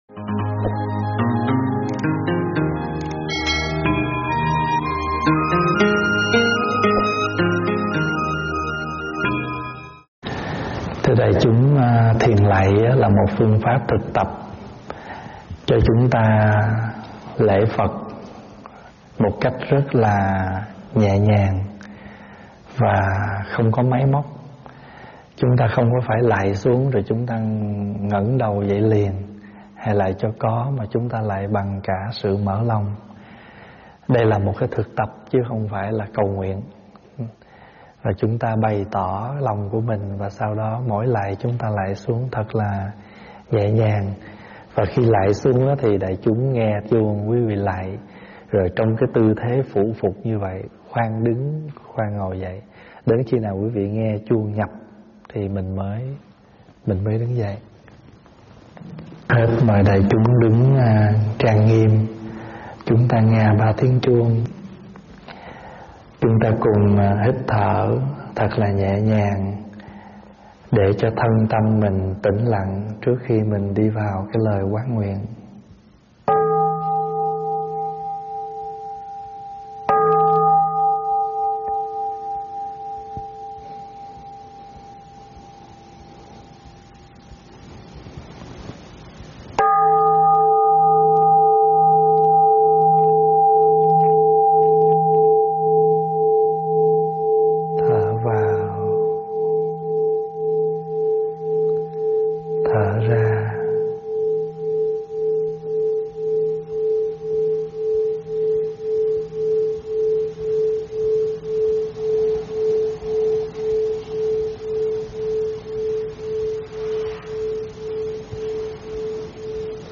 giảng tại Tv Tây Thiên